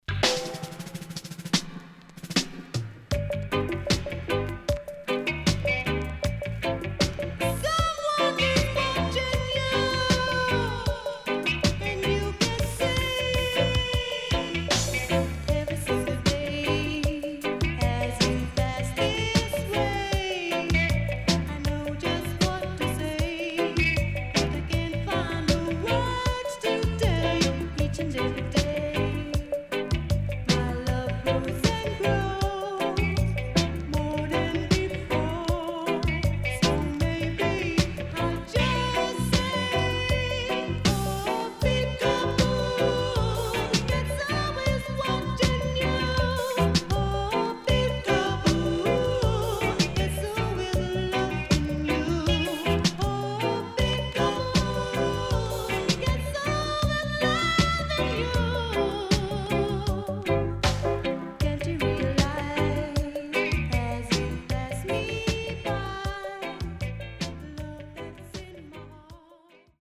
CONDITION SIDE A:VG+
SIDE A:プレス起因により少しチリノイズ入りますが良好です。